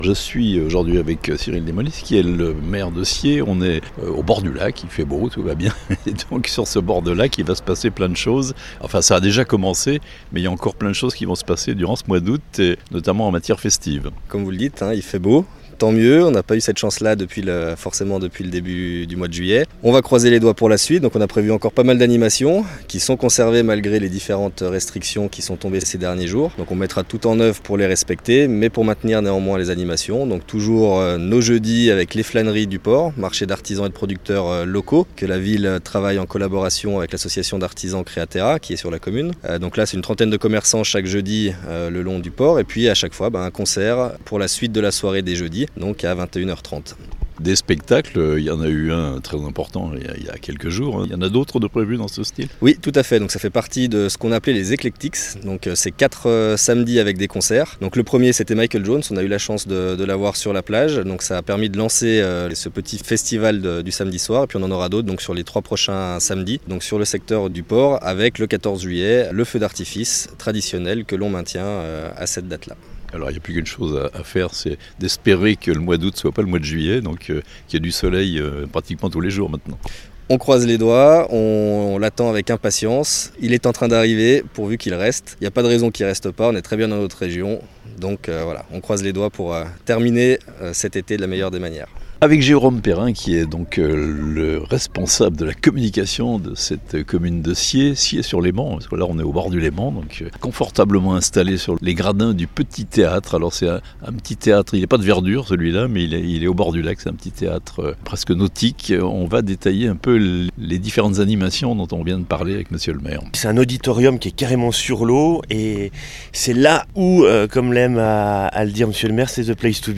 Tour d'horizon de ces animations estivales avec Cyril Démolis, le Maire de la commune